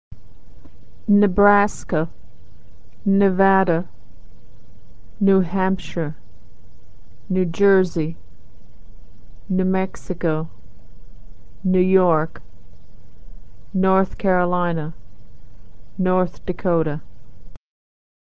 Consonant Sound /n/ - Practice - States - Authentic American Pronunciation
Consonant Sound Voiced /n/